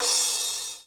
hat_10.wav